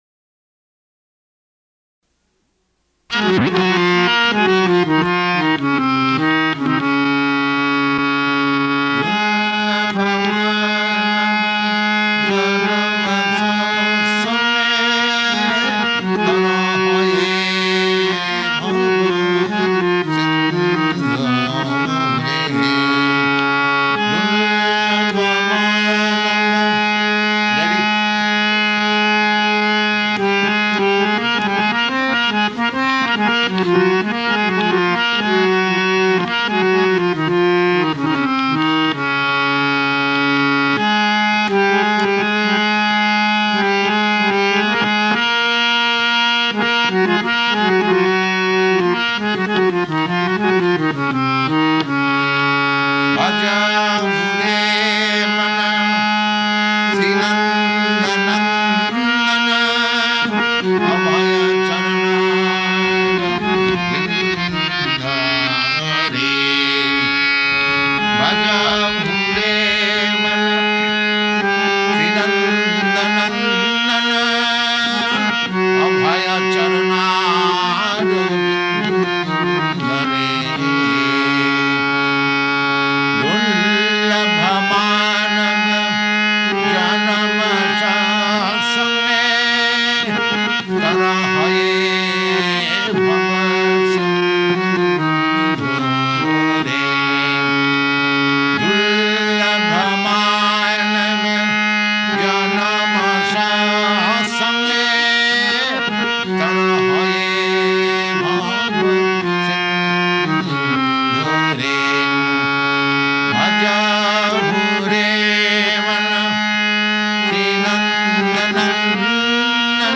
Purport to Bhajahu Re Mana --:-- --:-- Type: Purport Dated: March 16th 1967 Location: San Francisco Audio file: 670316PU-SAN_FRANCISCO.mp3 Prabhupāda: Bhajahū re mana śrī-nanda-nandana-abhaya-caraṇāravinda re.